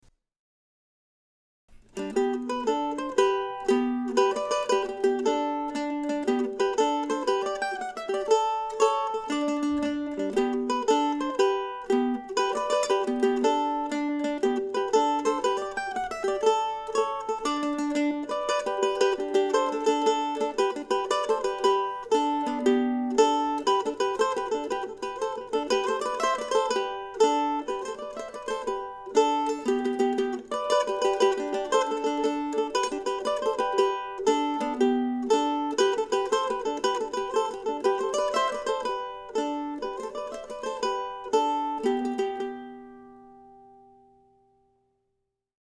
I also recorded the third of William Bates' duettinos, another fun piece to play.